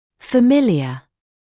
[fə’mɪlɪə][фэ’милиэ]знакомый; хорошо известный